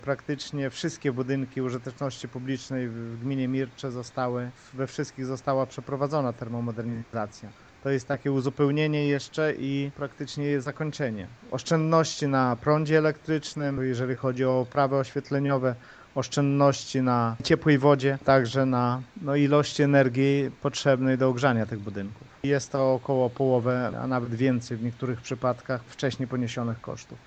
Biorąc pod uwagę oszczędności, które mamy po już wykonanej termomodernizacji z ostatnich 3 - 4 lat, nie mamy wątpliwości, że będą to dobrze wydane pieniądze - mówi wójt Lech Szopiński: